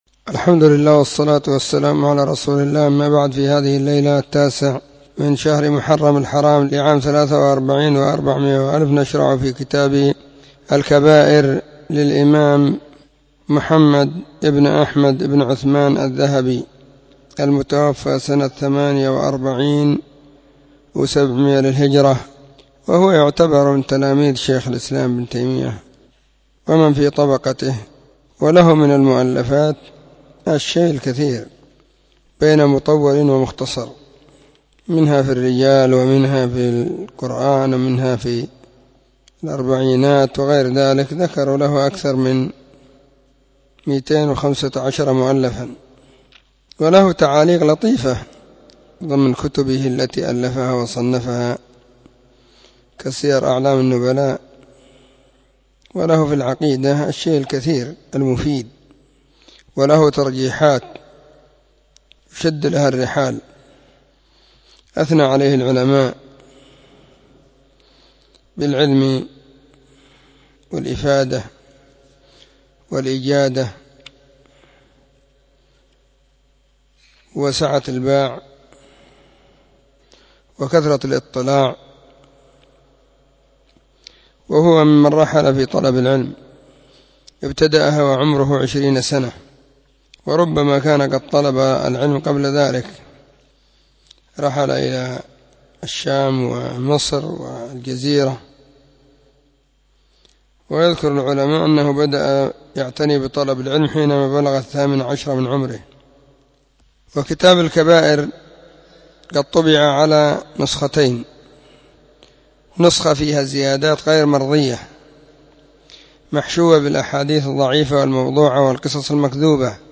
🕐 [بين مغرب وعشاء – الدرس الثاني]
كتاب-الكبائر-الدرس-1.mp3